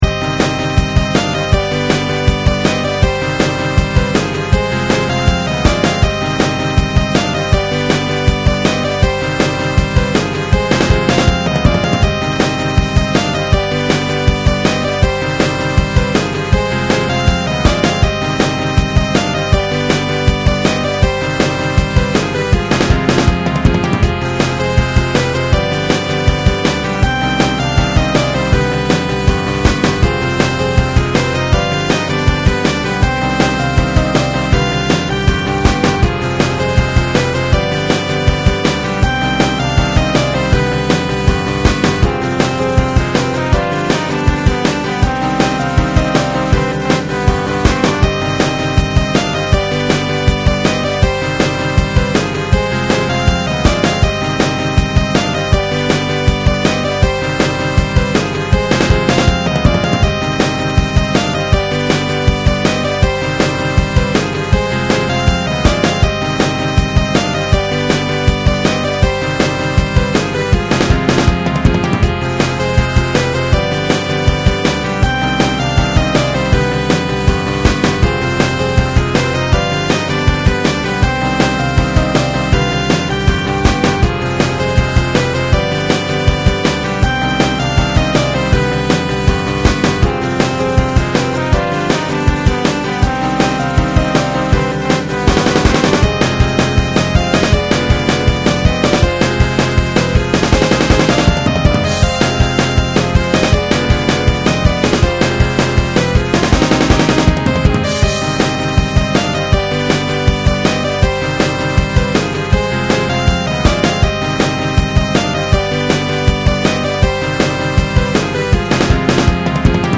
ステレオ   ドラムの音がパワフルで、サックスの音が綺麗に響いています。